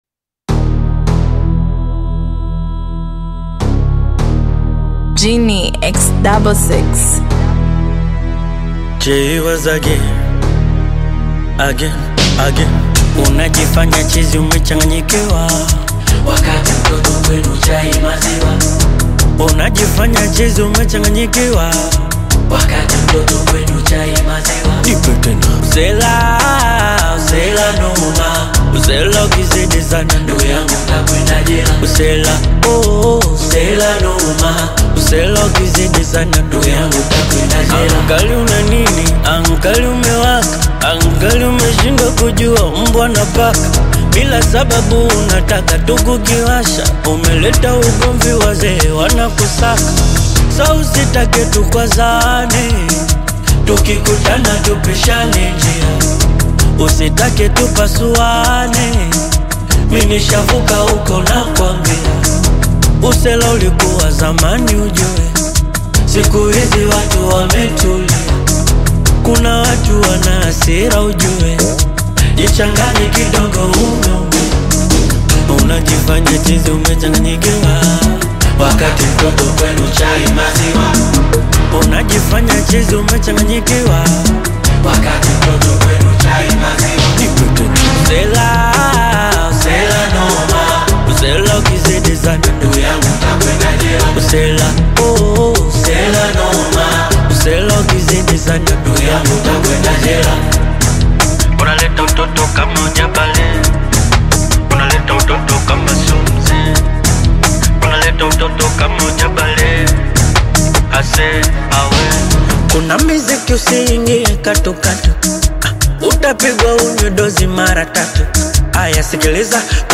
Bongo Fleva